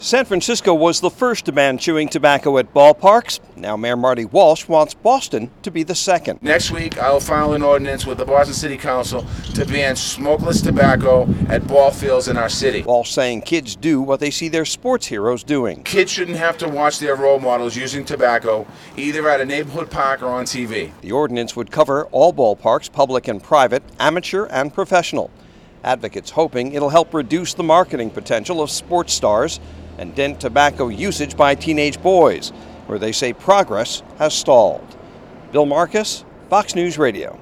(BOSTON) AUG 5 – BOSTON’S MAYOR WEDNESDAY AT A NEWS CONFERENCE WITH HEALTH AND SPORTS ADVOCATES SAYING NEXT WEEK HE’LL ASK THE CITY COUNCIL TO OUTLAW CHEWING TOBACCO USE ON ALL CITY BALL FIELDS INCLUDING FENWAY STARTING NEXT APRIL.